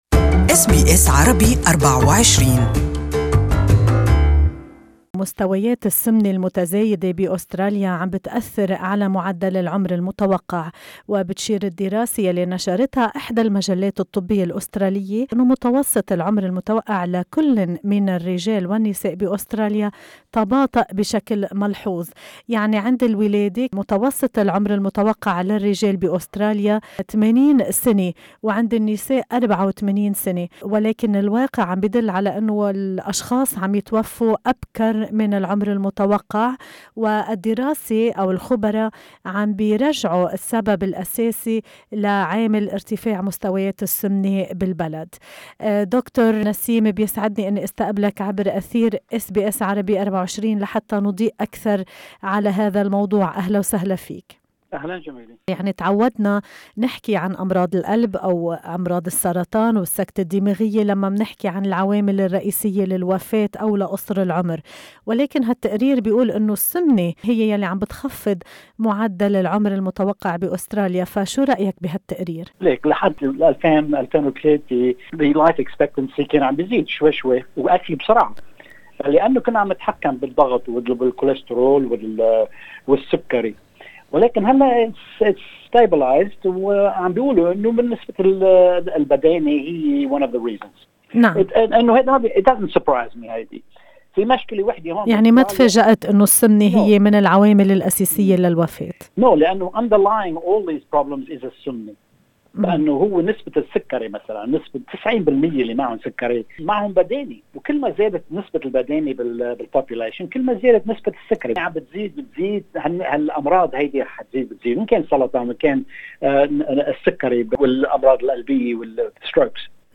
توجهنا عبر اثير اس بي اس 24